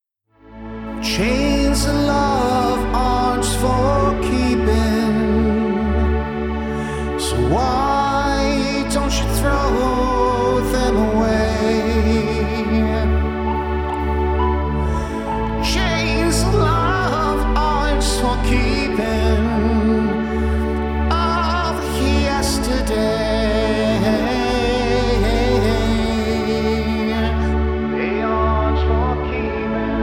Lead Vocals and Rhythm Guitar
Bass, Keyboards, and Harmony Vocals